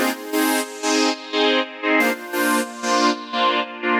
GnS_Pad-MiscB1:4_120-C.wav